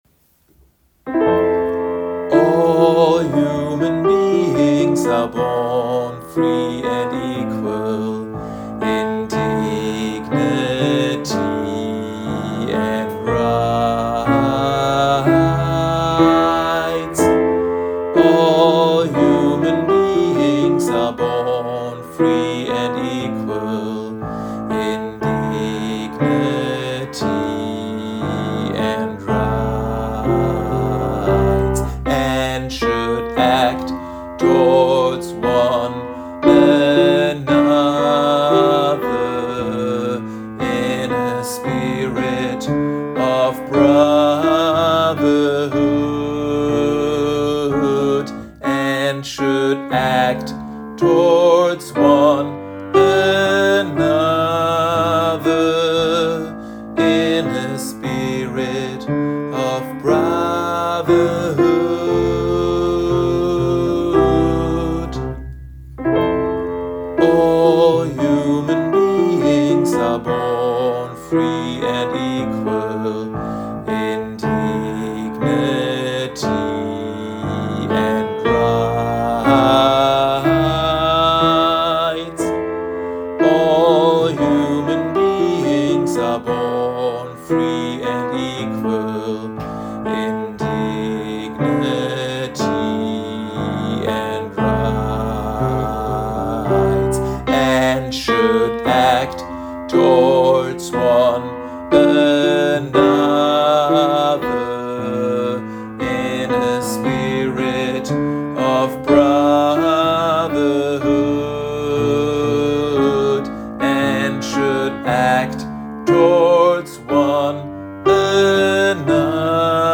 Übe-Dateien
ALT
Article01_Gospel_A.mp3